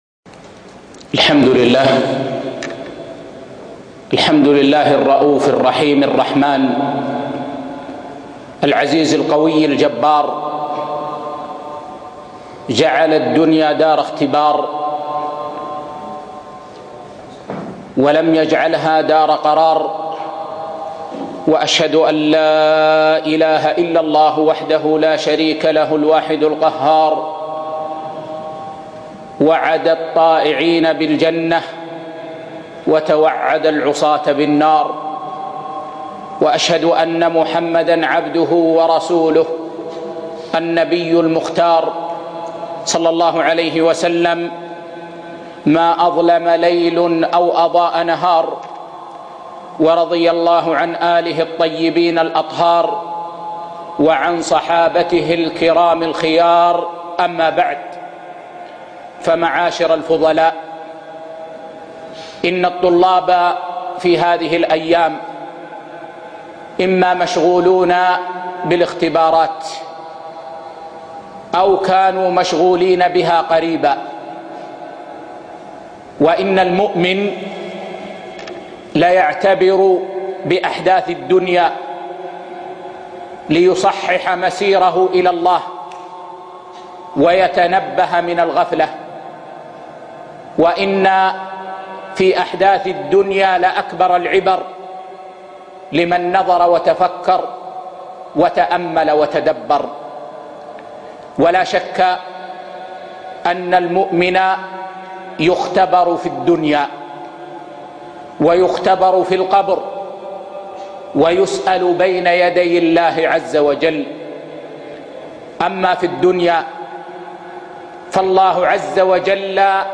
موعظة بليغة عن الابتلاء والموت والآخرة